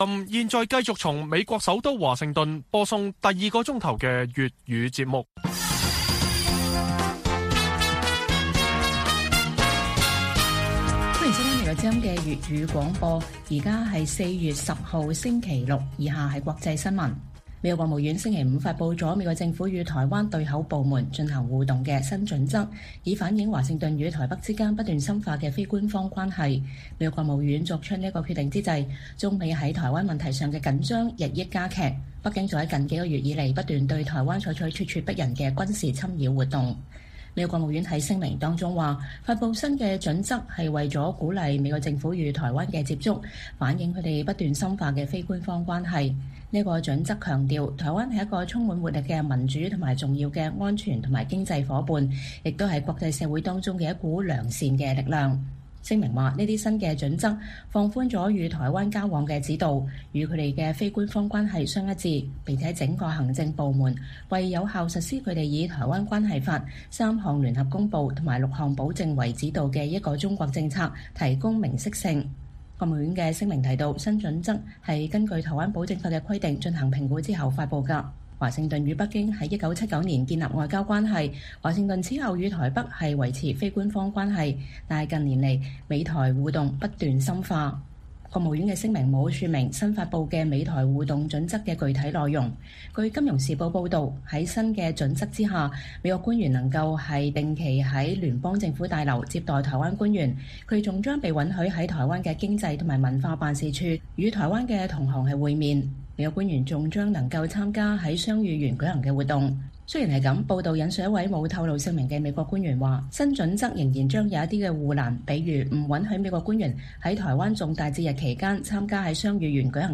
粵語新聞 晚上10-11點